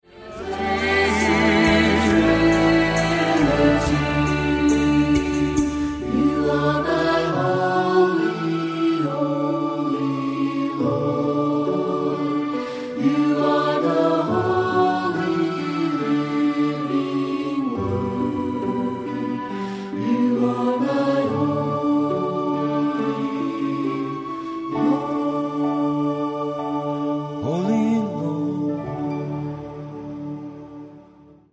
Worship Musical